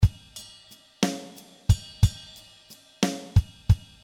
When you will have this loop song, you can be sure that in 90 bpm 6/8 signature, you are covered.
Loop patterns with close hihat, ride cymbals, tom fills, every thing that you need for love song.